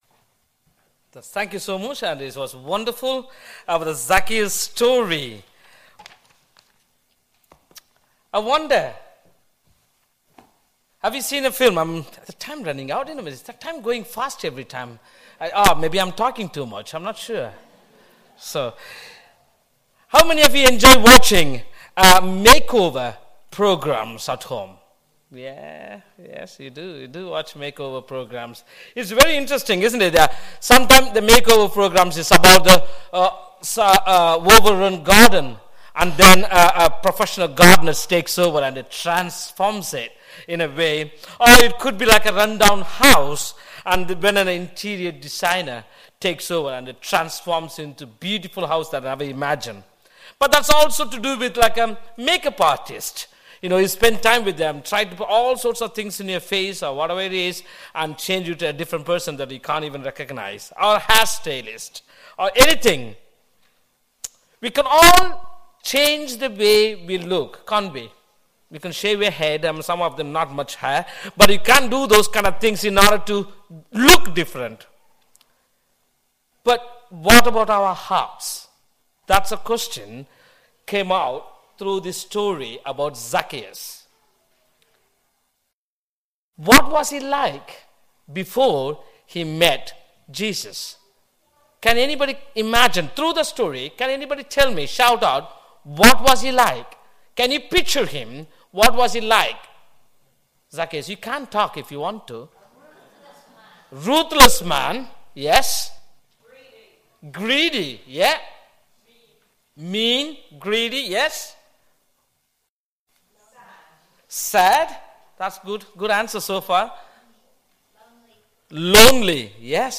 An audio version of the short talk is also available.
05-04-sermon.mp3